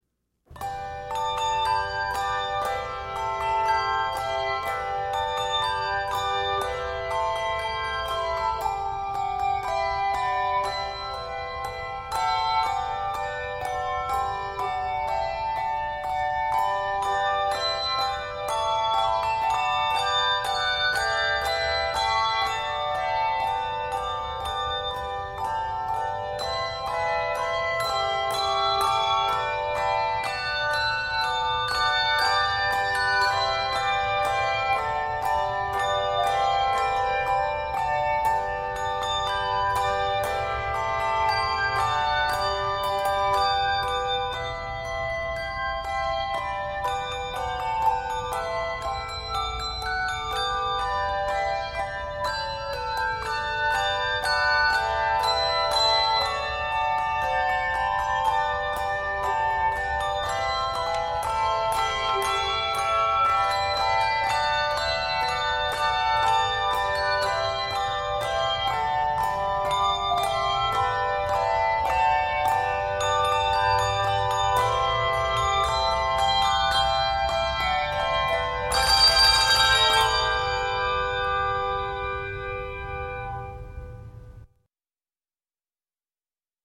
All are for 2-octave choirs and simply arranged.
Octaves: 2